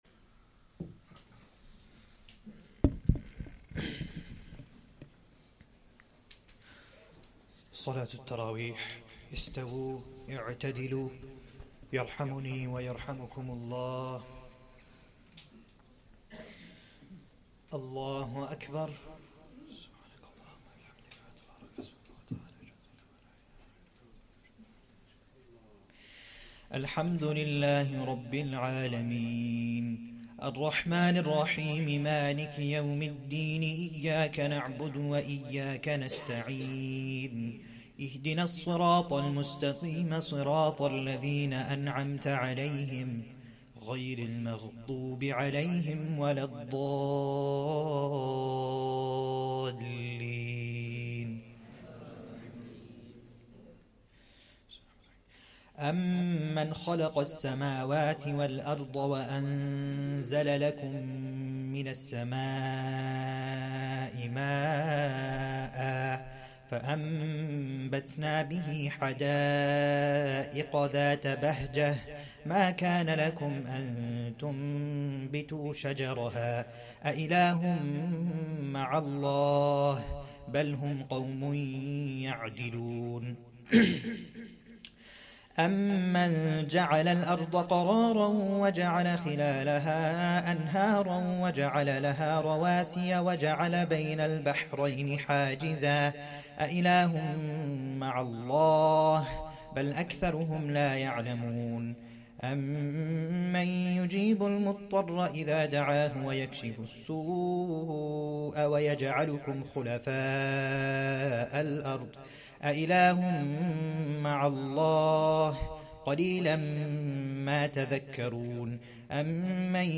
1st Tarawih prayer - 18th Ramadan 2024